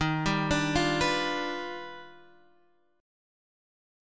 D# Augmented Major 9th